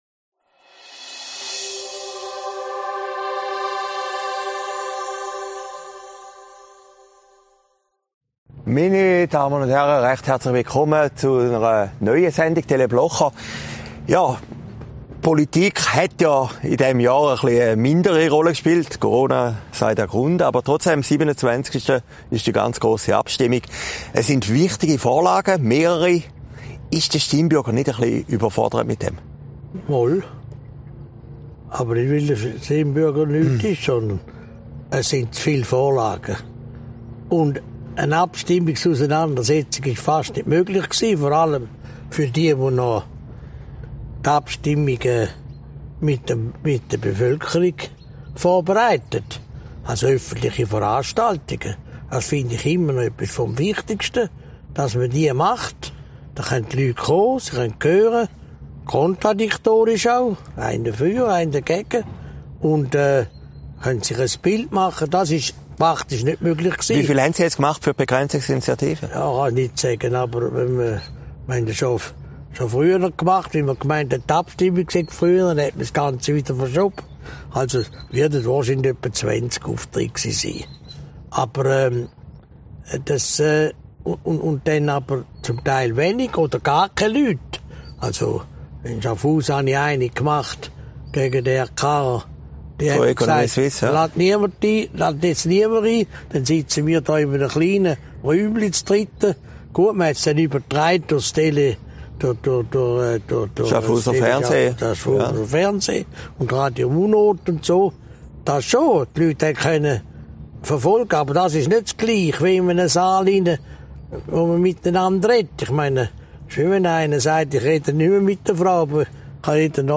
Aufgezeichnet in Herrliberg, 18. September 2020